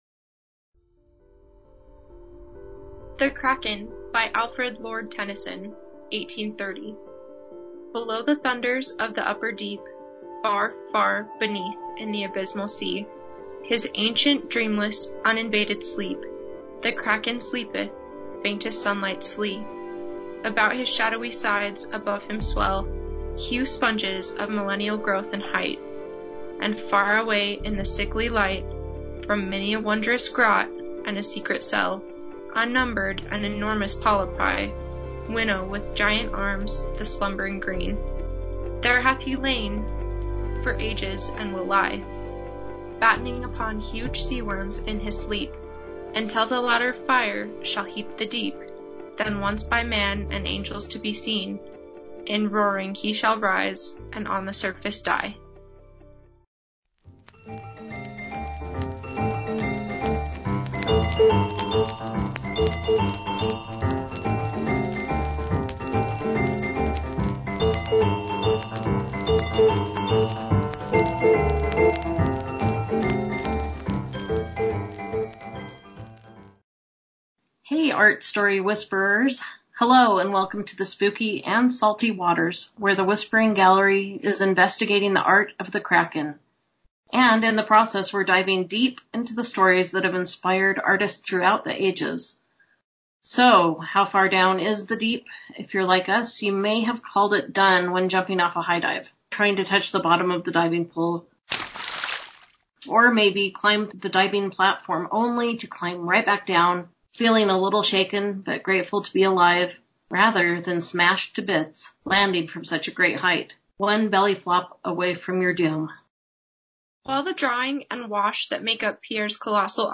We share a short reading from 20,000 Leagues Under the Sea by Jules Vern and some incredible stories.